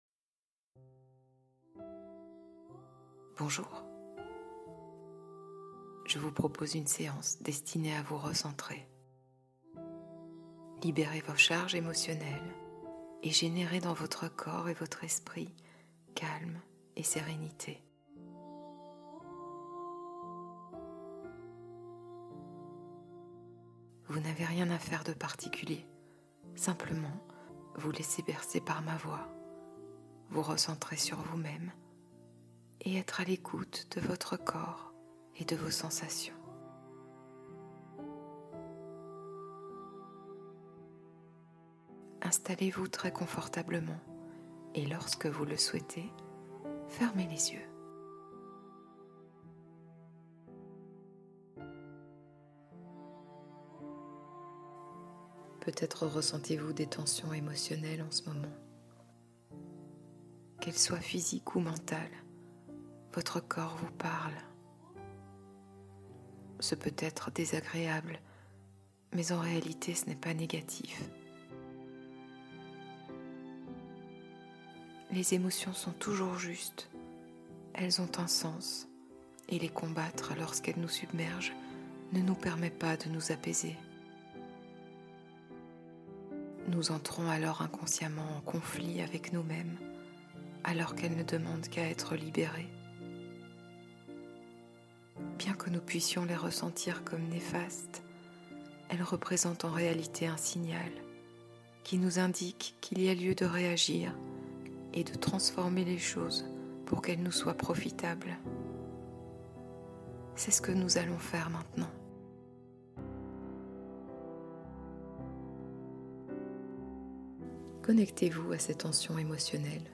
Sommeil au cœur des étoiles : hypnose réparatrice